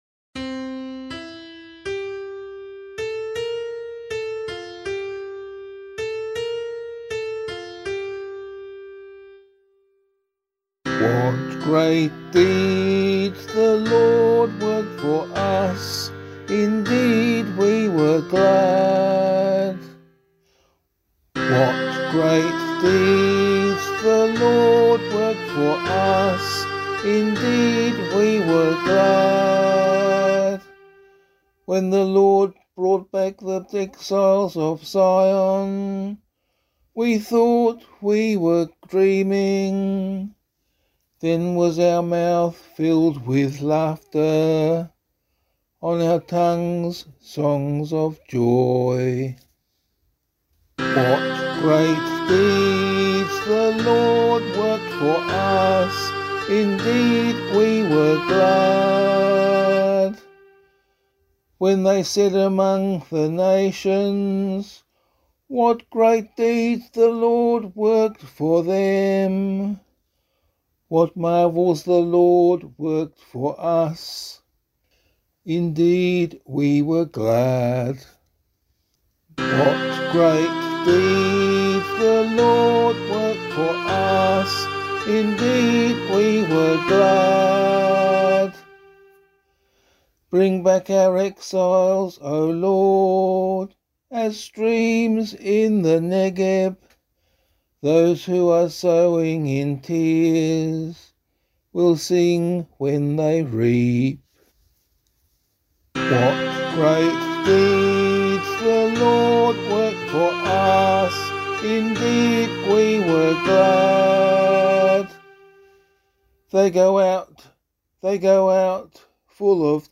002 Advent 2 Psalm C [APC - LiturgyShare + Meinrad 7] - vocal.mp3